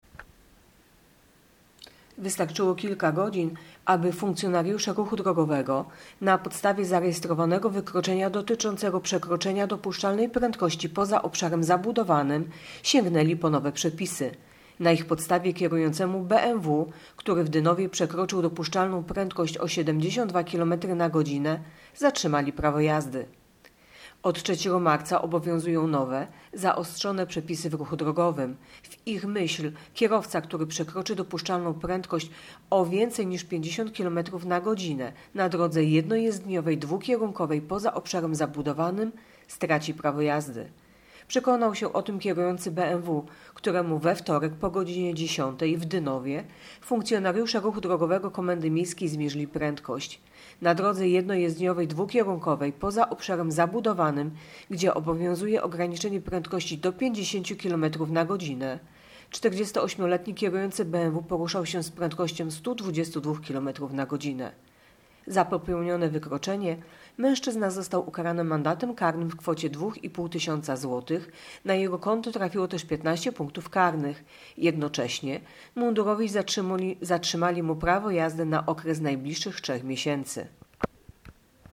Nagranie audio Mówi